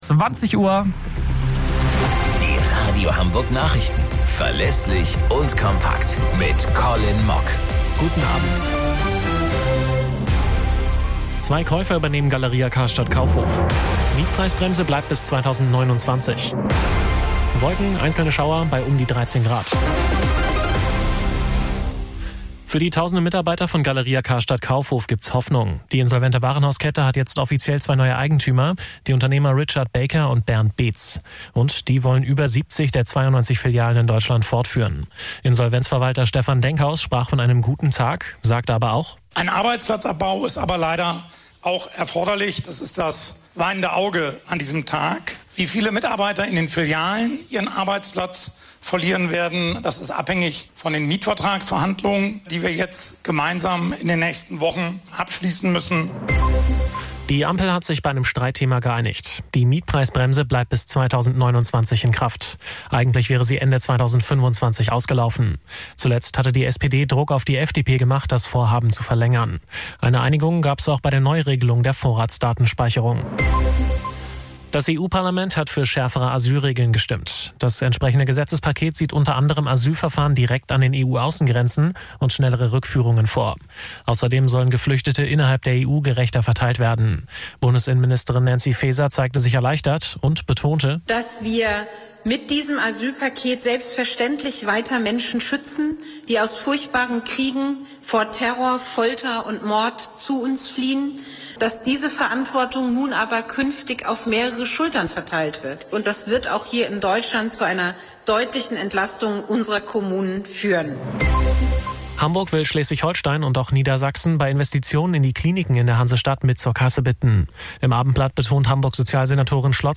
Radio Hamburg Nachrichten vom 10.04.2024 um 20 Uhr - 10.04.2024